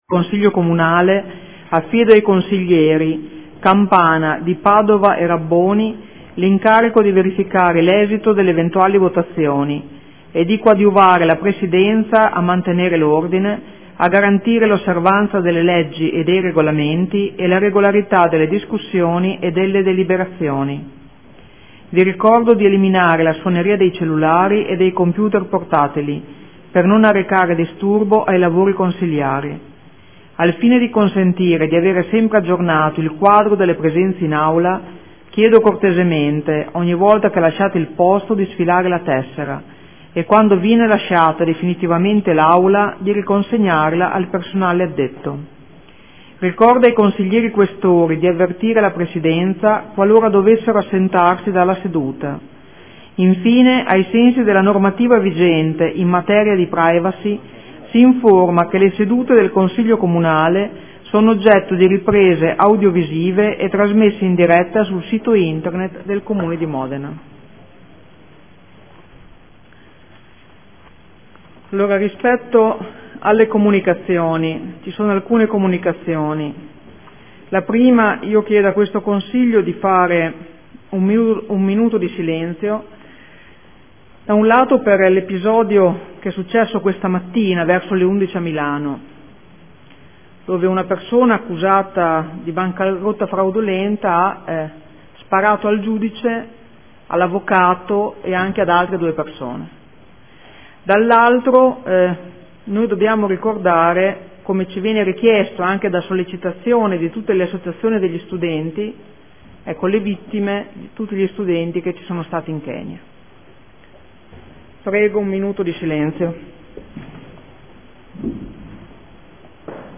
Seduta del 09/04/2015 Apre i lavori del Consiglio. Chiede un minuto di silenzio per la tragedia al Tribunale di Milano e per le vittime tra gli studenti in Kenya. Chiede una Commissione per verificare la sicurezza su asse viario di Viale Italia dopo la tragedia di lunedi.
Presidentessa